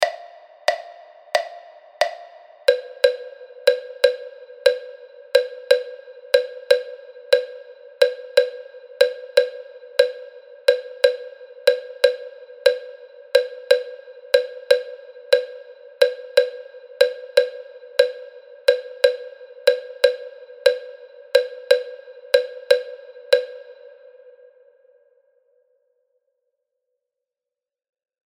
Click the audio file and sing an improvised melody using the tone set below to the rhythm accompaniment.
roll-with-the-rhythm.mp3